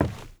WOOD.2.wav